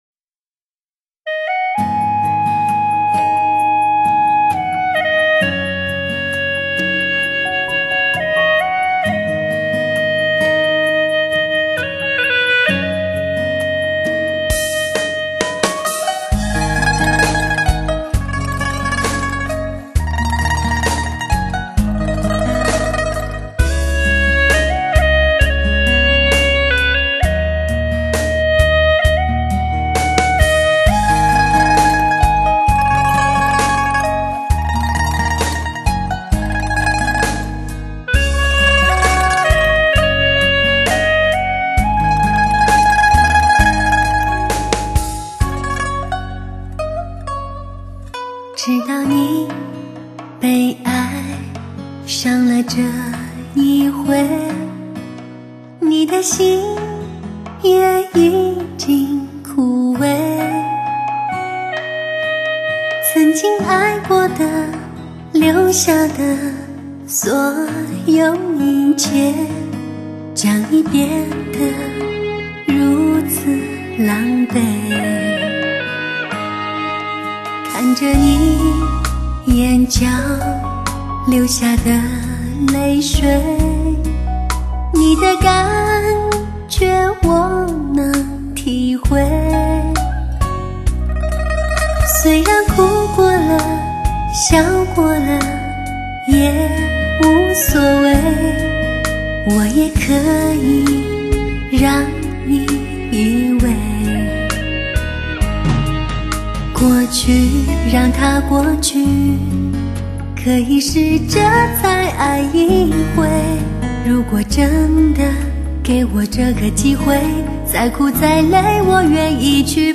高品质环绕女声 点燃麦克风发烧味道
强势高清解码还原真声 带来超乎想象的震撼级高临场感